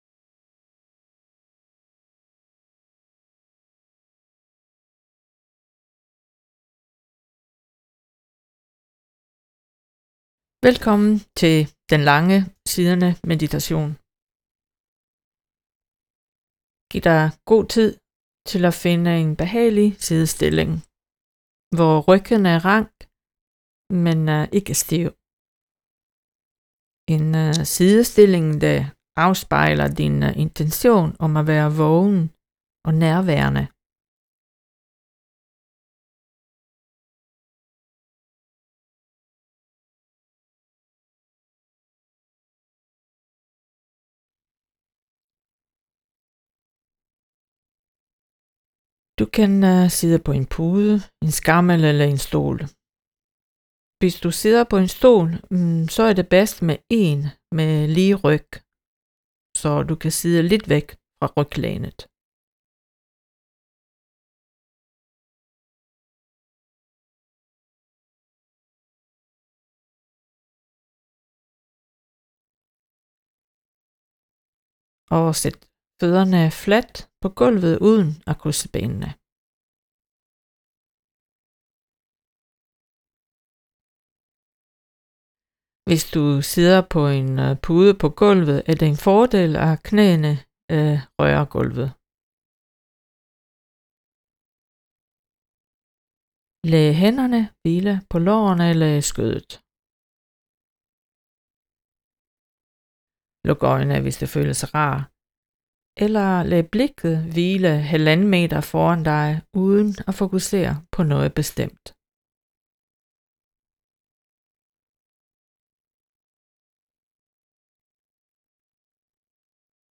Mindfulnessøvelser